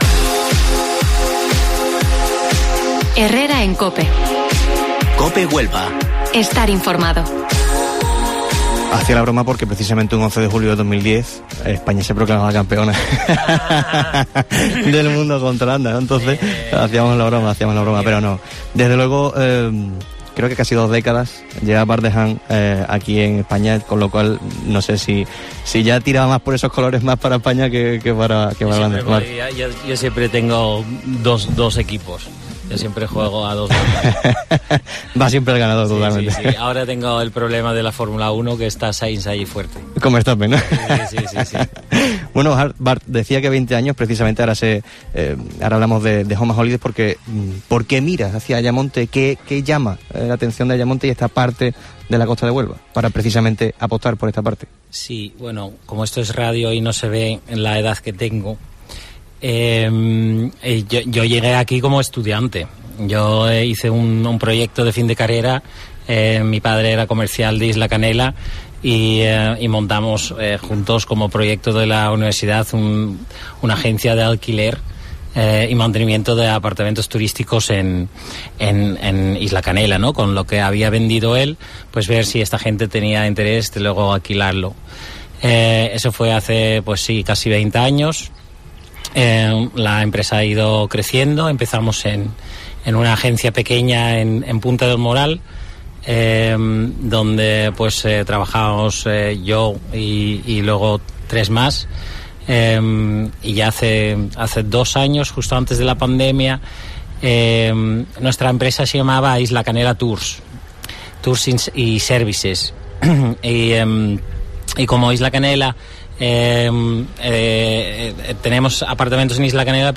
Herrera en COPE Huelva desde la Posada El Convento Mercedario